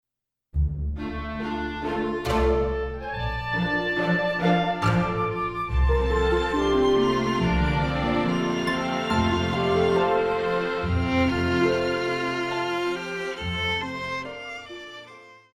古典
小提琴
管弦樂團
童謠,經典曲目,傳統歌曲／民謠,古典音樂
演奏曲
獨奏與伴奏
有主奏
有節拍器